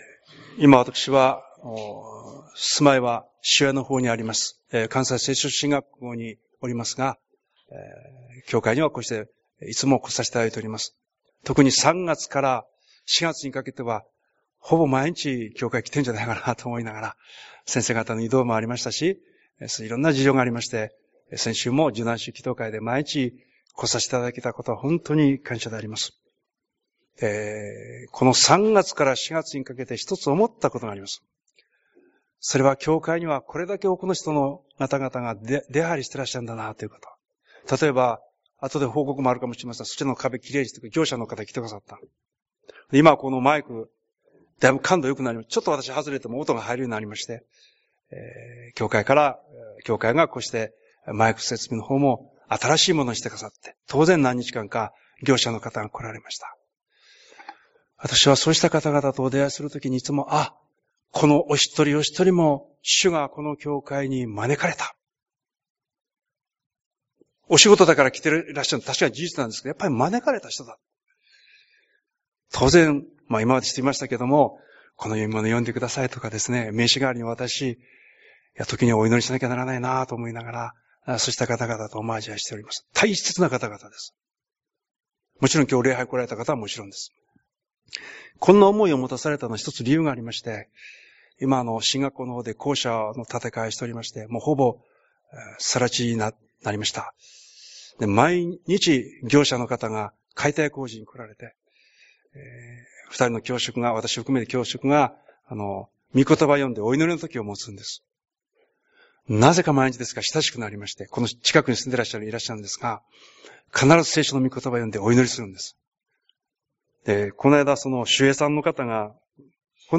「主が私の愛を完成してくださる」 宣 教